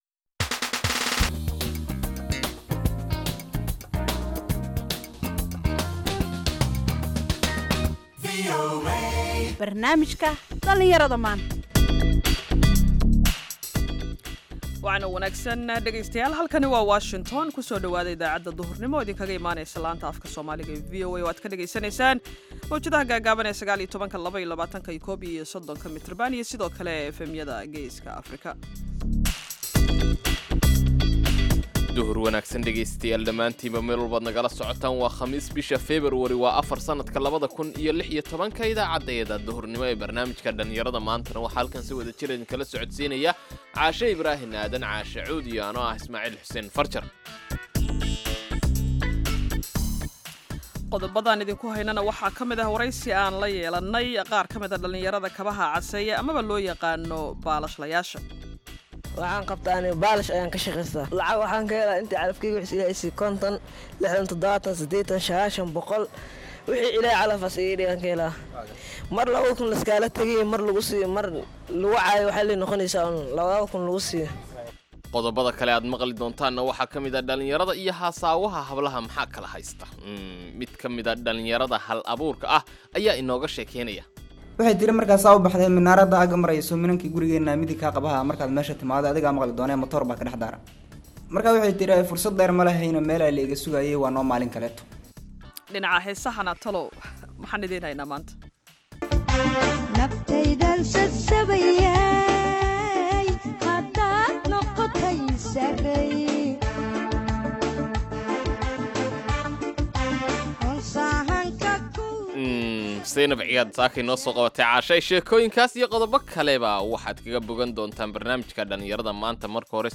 Idaacadda Duhurnimo waxaad qeybta hore ku maqli kartaa wararka ugu waaweyn ee Soomaaliya iyo Caalamka. Qeybta danbe ee idaacaddu waxay idiin soo gudbinaysaa barnaamijyo ku saabsan dhalinyarada maanta.